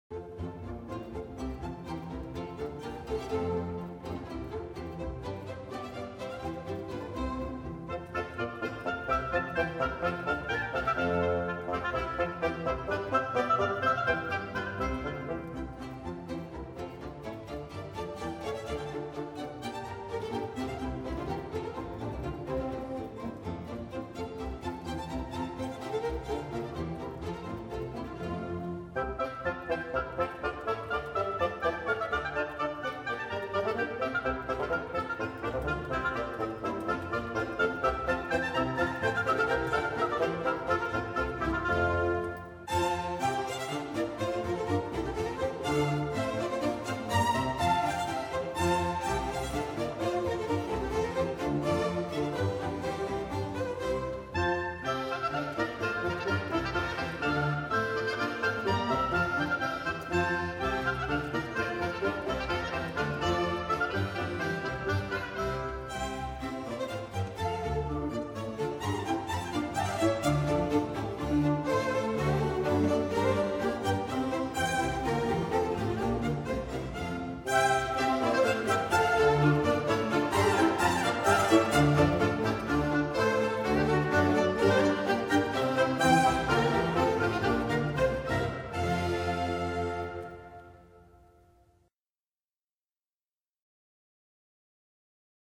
布列舞曲和号笛舞曲
布列舞曲大约起源于17世纪初，是一种轻快的两拍子法国舞曲，以弱起的拍子开始，即小节的第三个四分音符。